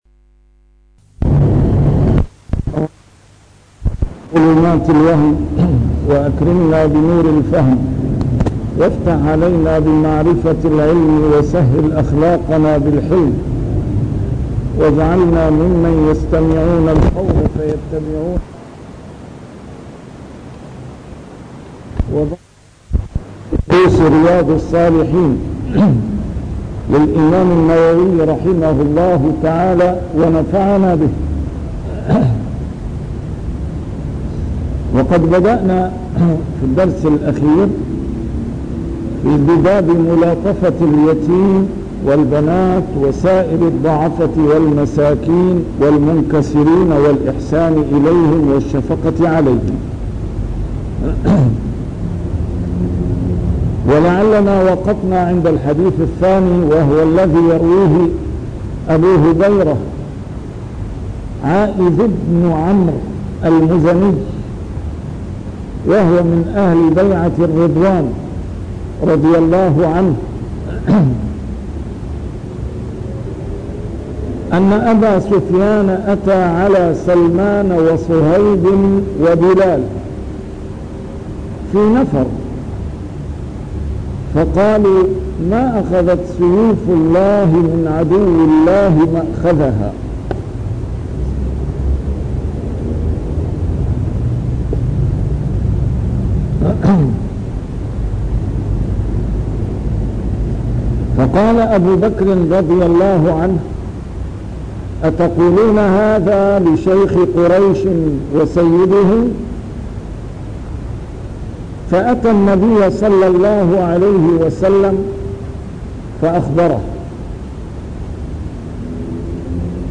A MARTYR SCHOLAR: IMAM MUHAMMAD SAEED RAMADAN AL-BOUTI - الدروس العلمية - شرح كتاب رياض الصالحين - 371- شرح رياض الصالحين: ملاطفة اليتيم والبنات